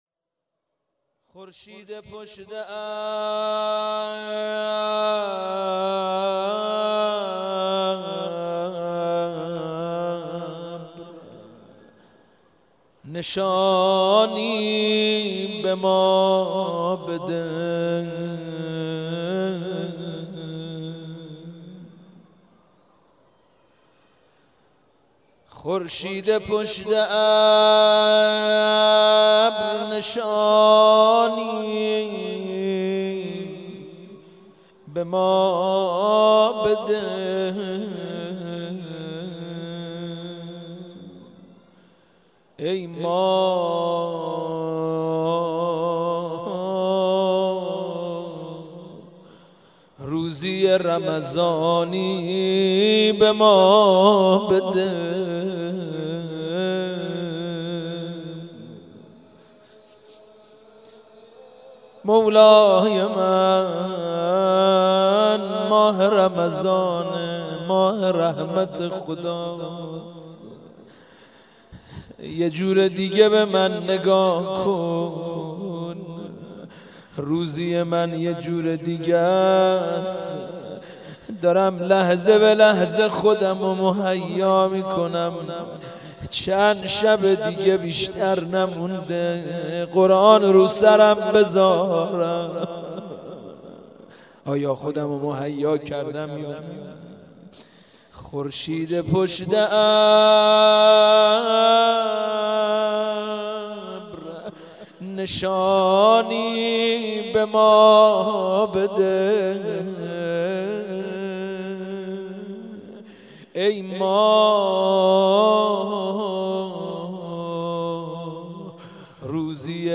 مداحی
در حرم حضرت معصومه(س) برگزار گردید.
مناجات